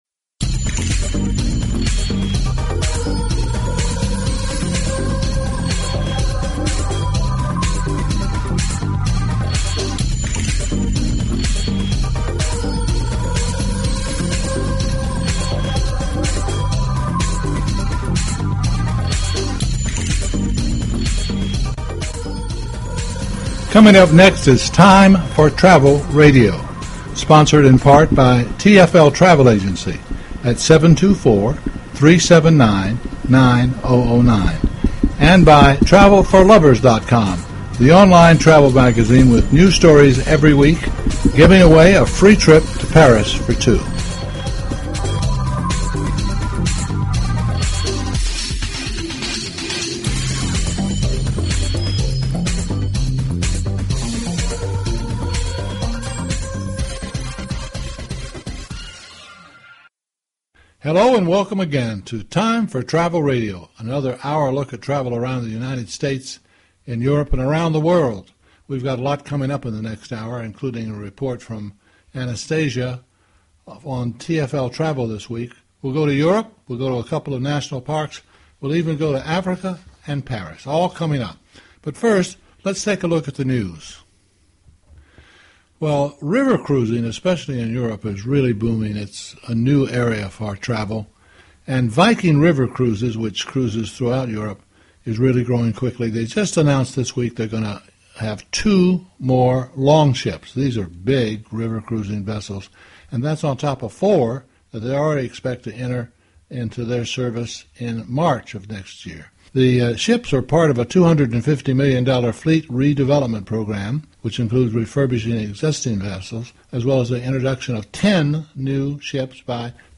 Talk Show Episode, Audio Podcast, Time_for_Travel_Radio and Courtesy of BBS Radio on , show guests , about , categorized as